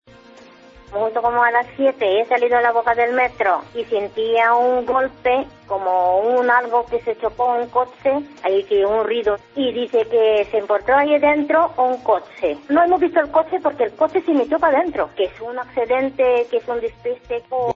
Una testigo cuenta en COPE lo ocurrido
Entrevistas